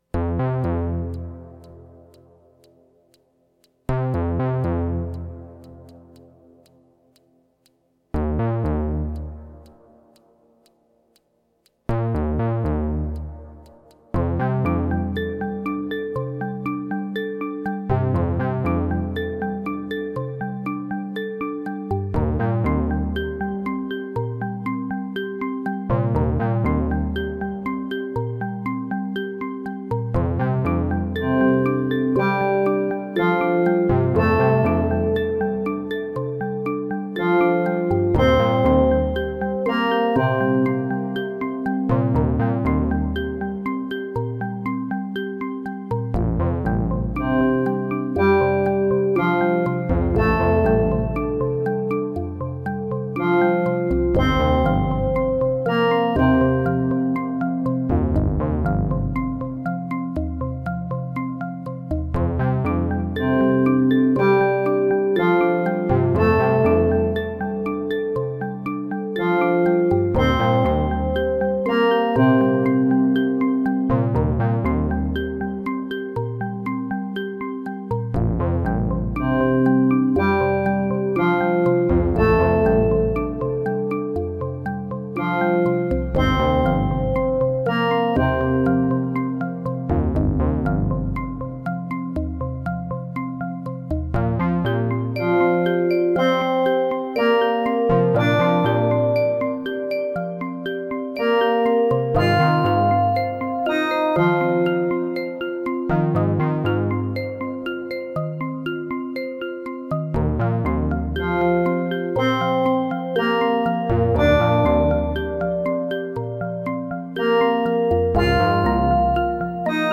• AE Modular synthesizer rack shown as configured here, notably including three GRAINS modules and a 555 VCO pushed through a Wavefolder.
Much too muddy. Too reverb and the decays are way too long. The bassline's sound generation procedure causes it to sound out of tune, particularly flat, in many places; it'd have been better if I had pitched it up a bit.